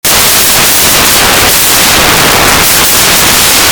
All I get is scratchy 0dbfs bitstream noise with AVCHD files from a Sony camera.
ATTACHED is a file that demonstrates what the audio sounds like. (WARNING, it's loud)
AVCHDDolbyBitstream.mp3